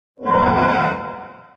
255081e1ee Divergent / mods / Soundscape Overhaul / gamedata / sounds / monsters / poltergeist / attack_6.ogg 10 KiB (Stored with Git LFS) Raw History
attack_6.ogg